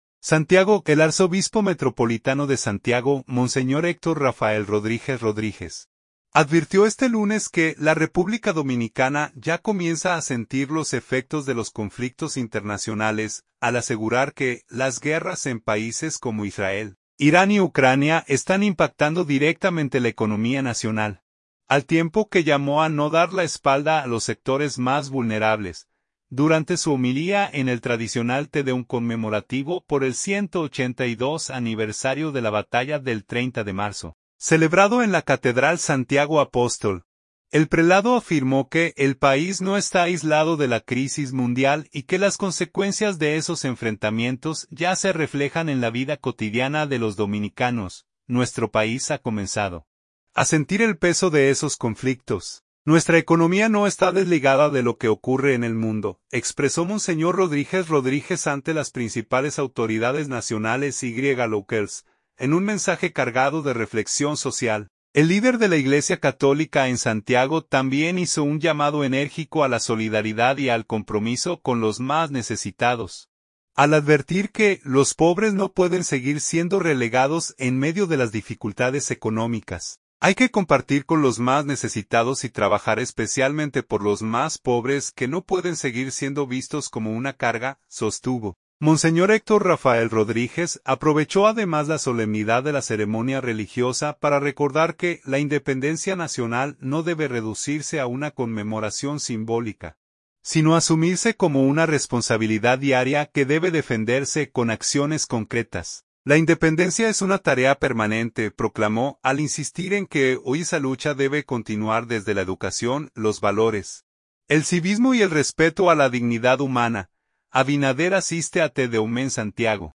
Durante su homilía en el tradicional Tedeum conmemorativo por el 182 aniversario de la Batalla del 30 de Marzo, celebrado en la Catedral Santiago Apóstol, el prelado afirmó que el país no está aislado de la crisis mundial y que las consecuencias de esos enfrentamientos ya se reflejan en la vida cotidiana de los dominicanos.